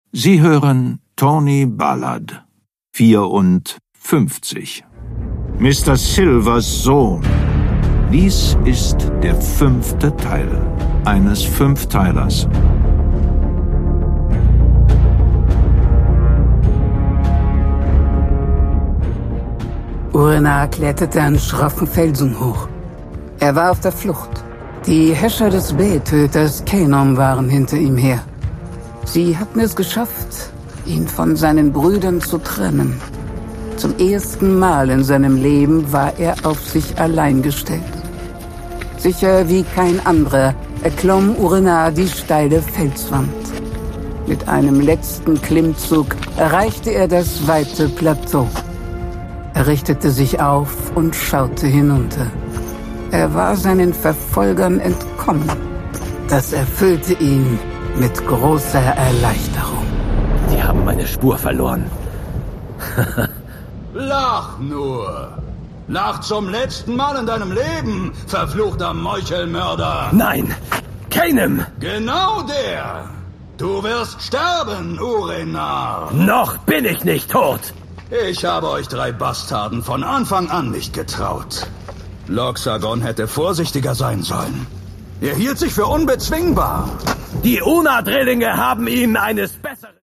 Produkttyp: Hörspiel-Download